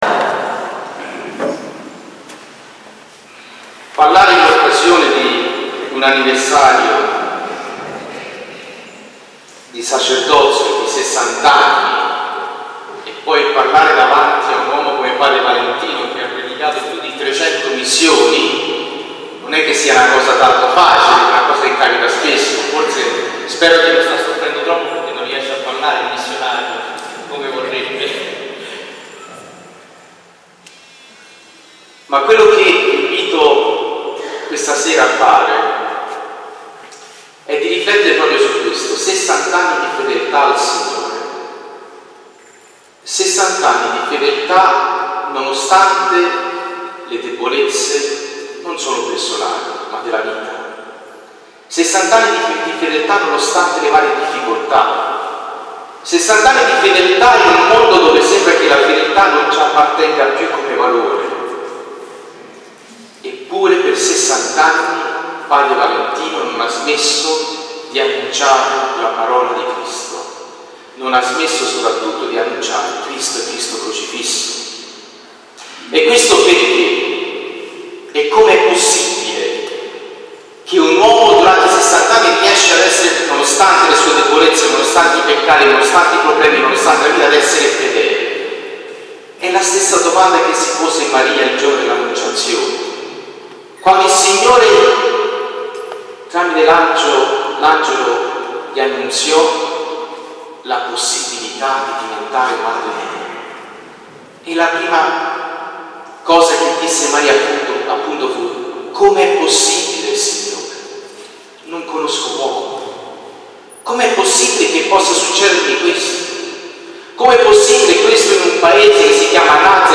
Molto solenne e spiritualmente sentita e partecipata la celebrazione dell’Eucaristia,
omelia-festa.mp3